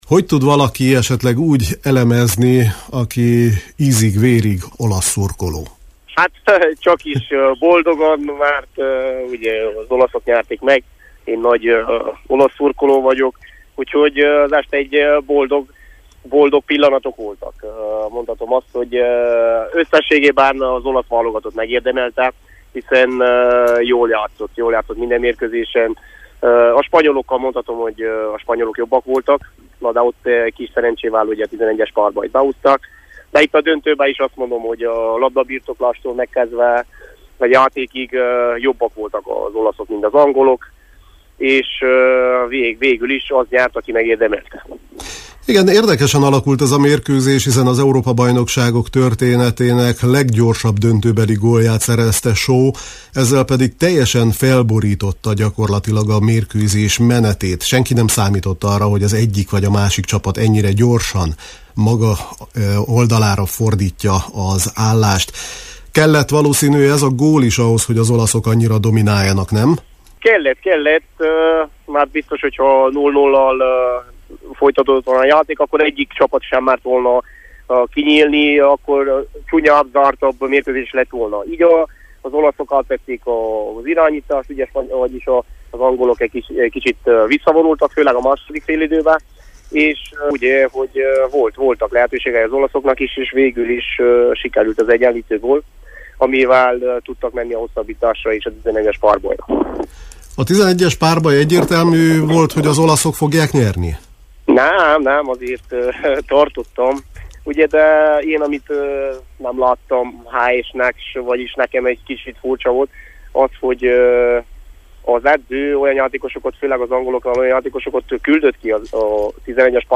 Előbb az Európa-bajnokságot elemeztük, majd a Sepsi-OSK pénteki bajnoki újrakezdéséről beszélgettünk.